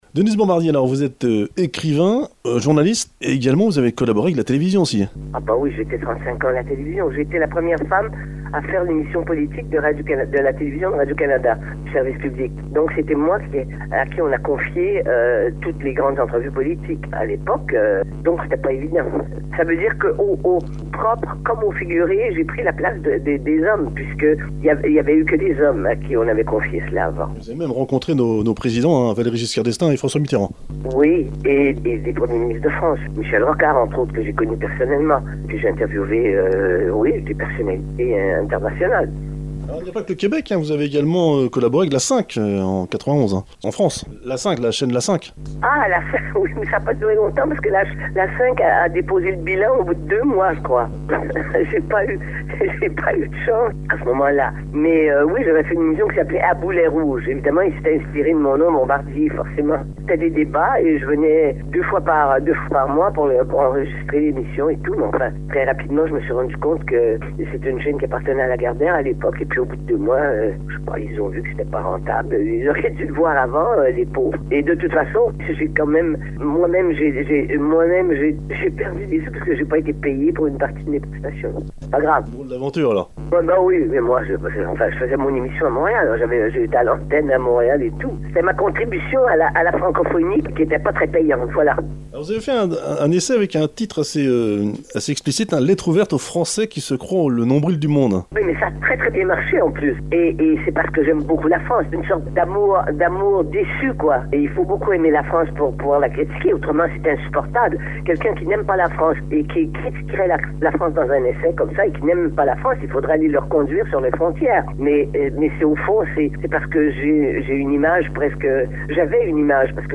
Interview de la journaliste et écrivain québécoise Denise Bombardier (qui défend la francophonie et qui a publié de nombreux sur le sujet)